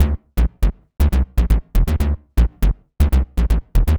TSNRG2 Bassline 027.wav